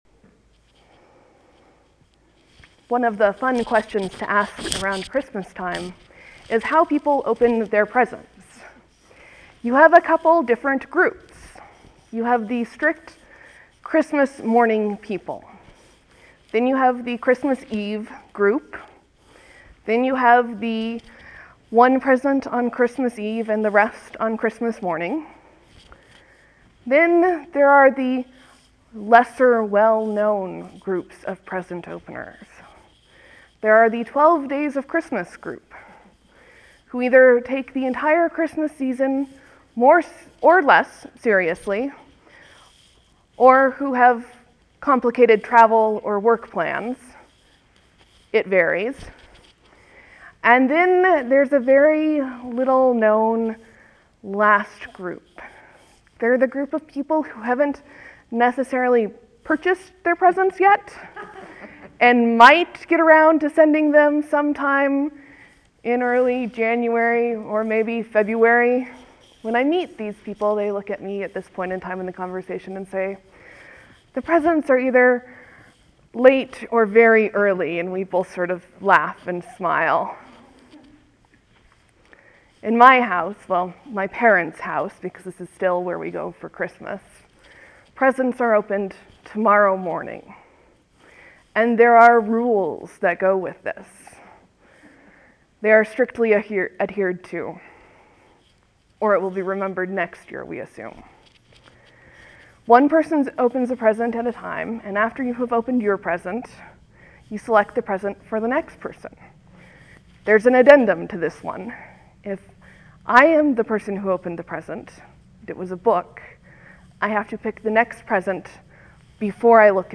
(There will be a few moments of silence before the sermon begins. Thank you for your patience.)